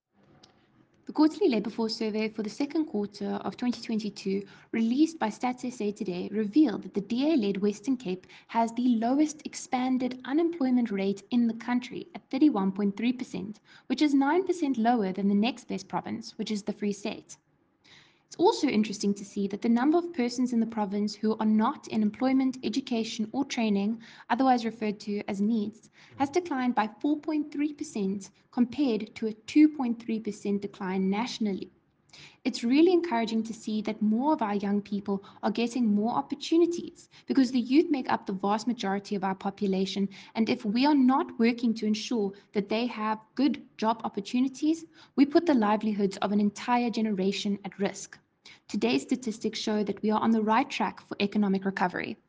English audio clip from MPP Cayla Murray attached.